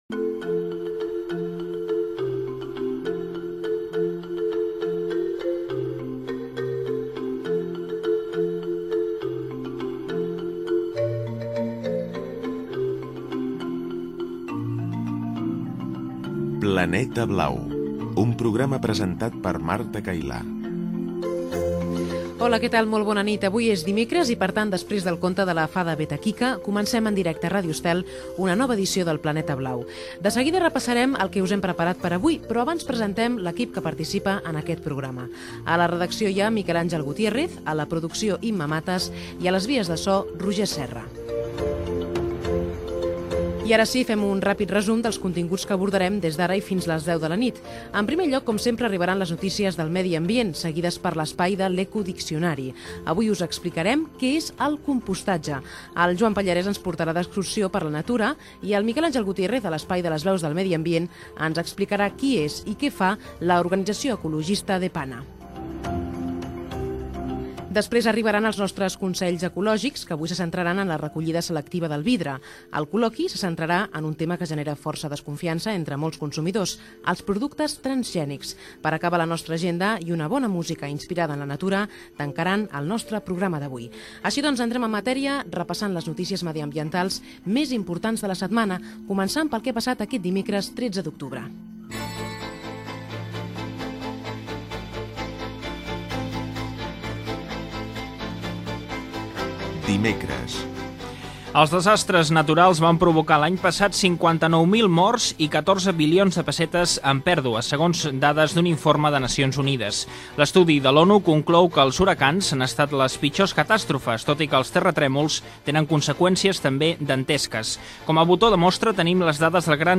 Careta del programa, presentació, equip, sumari, notícies medioambientals de la setmana, indicatiu del programa, "L'eco diccionari" sobre el compostatge, l'hora, l'excursió, publicitat, l'associació DEPANA, indicatiu, "Consells ecològics" per reciclar el vide, publicitat Gènere radiofònic Divulgació